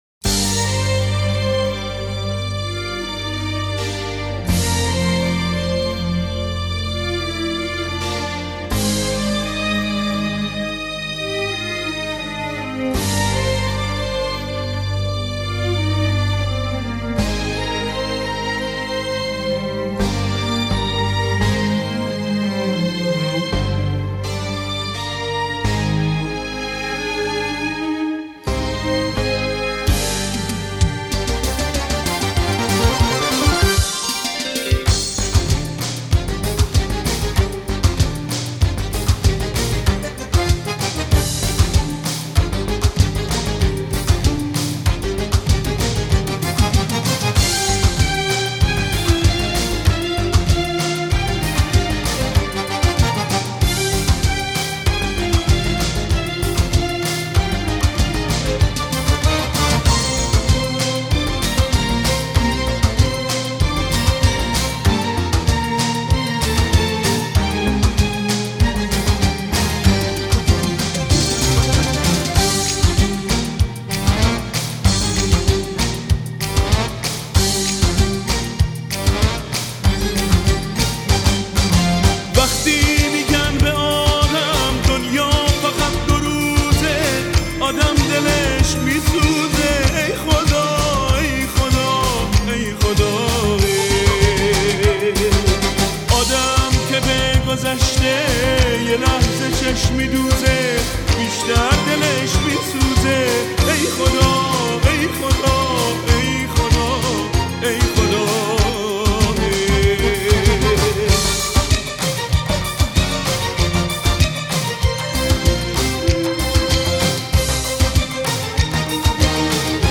اهنگ غمگین
صدای نرم و قدرتمندی داره